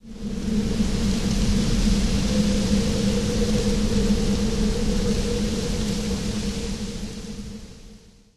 Звук дождя и завывание ветра.